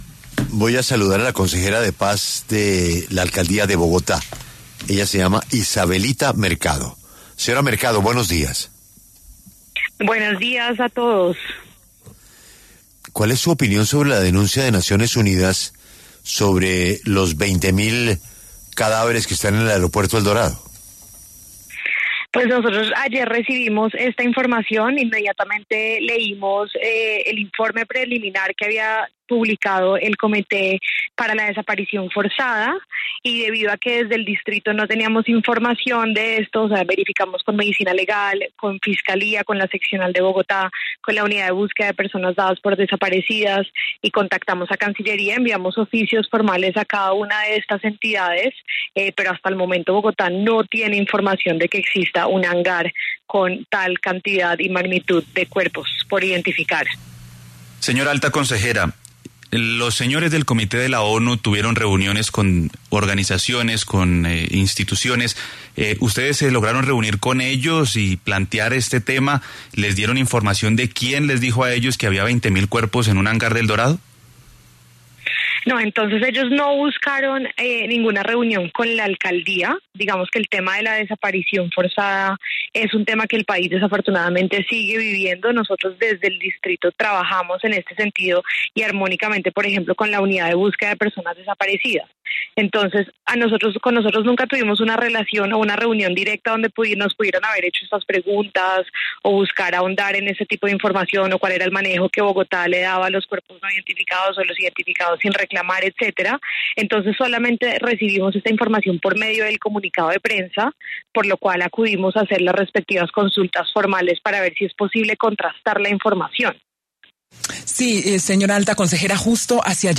La alta consejera para las Víctimas, Paz y Reconciliación de la Alcaldía Mayor, Isabelita Mercado, aclaró en entrevista con La W que, hasta ahora, no hay evidencia que respalde esta afirmación.